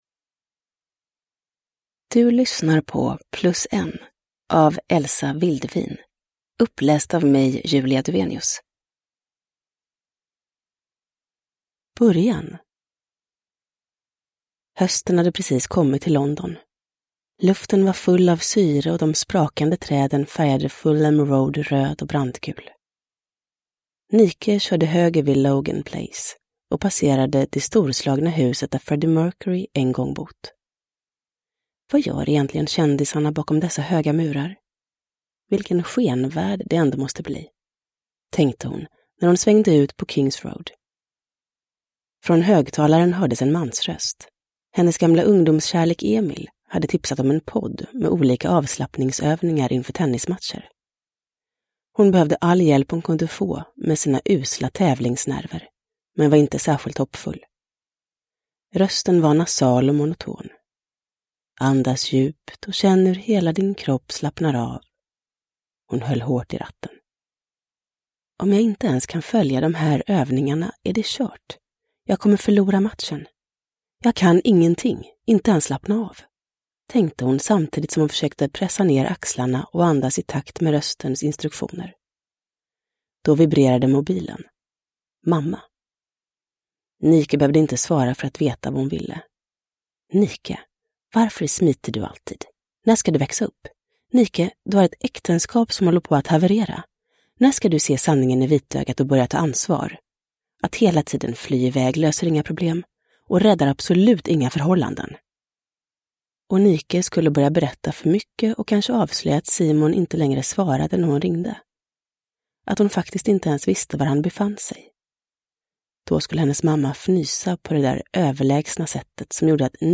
Plus en – Ljudbok – Laddas ner
Uppläsare: Julia Dufvenius